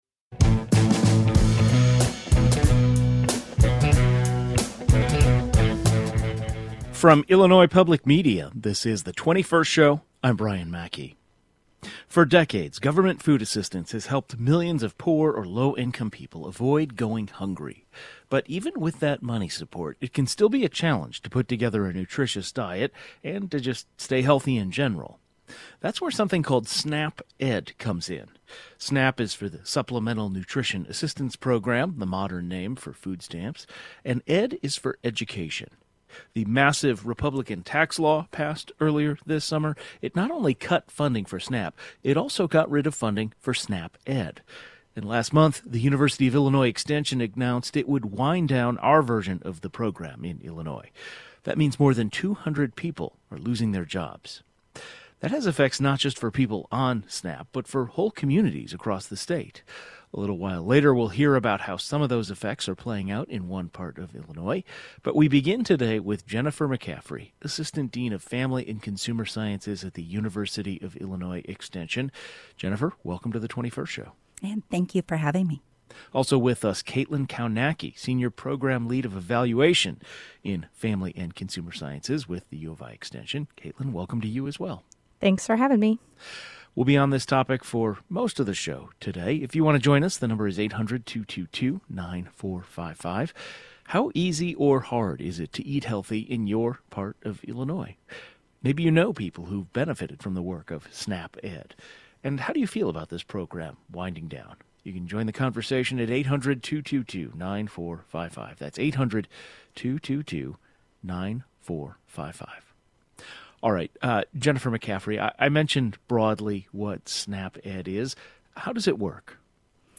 A program meant to help SNAP recipients make healthier choices and stretch their dollars further is losing federal funding. The 21st Show is Illinois' statewide weekday public radio talk show, connecting Illinois and bringing you the news, culture, and stories that matter to the 21st state.